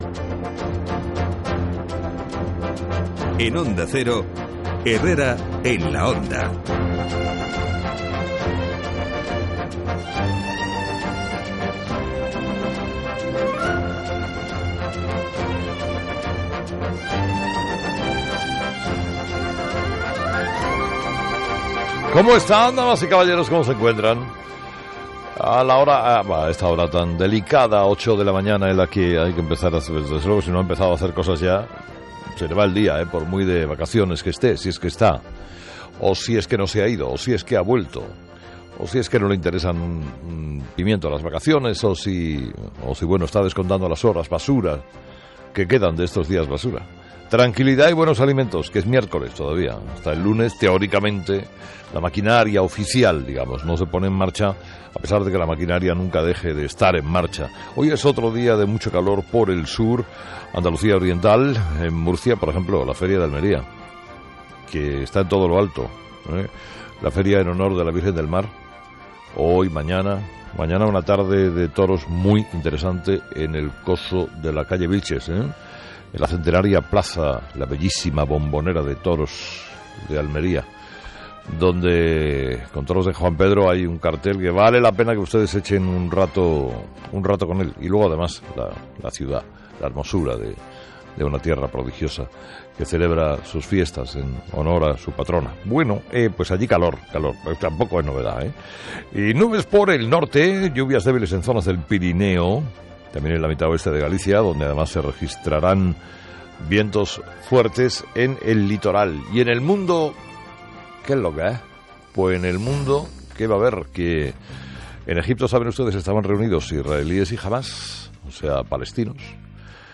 27/08/2014 Editorial de Carlos Herrera: "Mas no celebrará la consulta si el Constitucional la prohíbe"